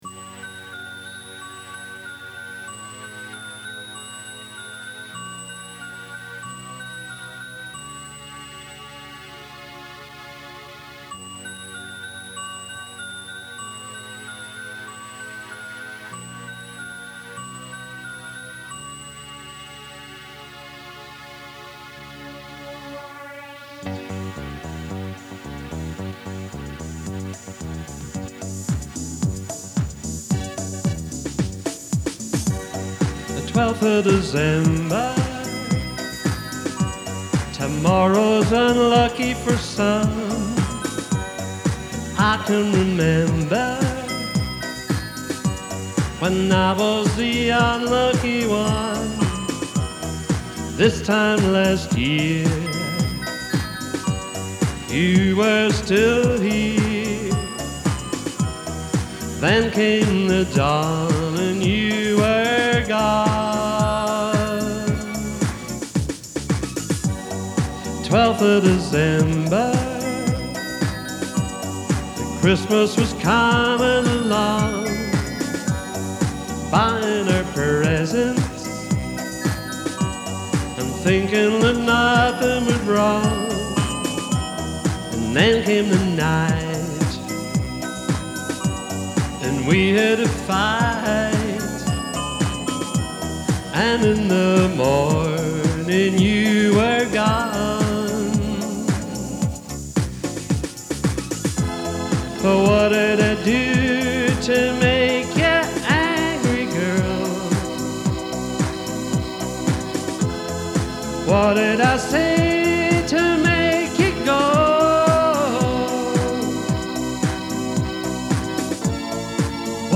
Below is the link to the Full studio version.